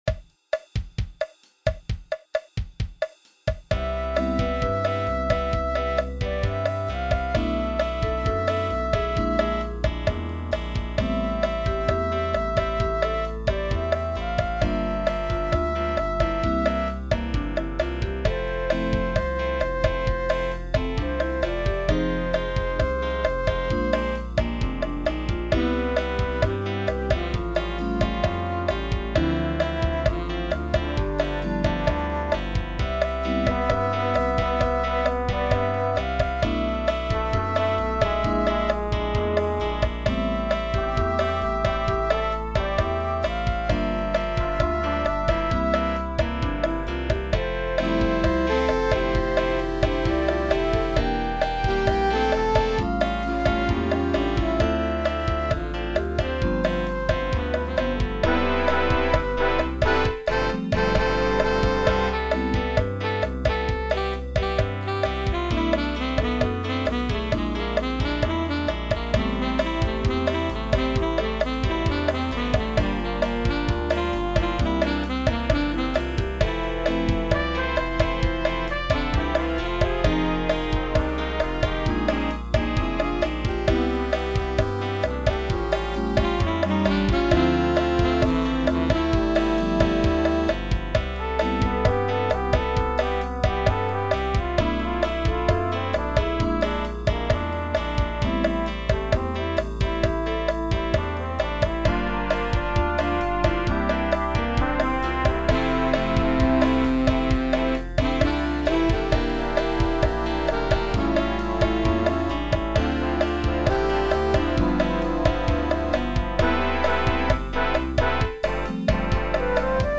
Latin Jazz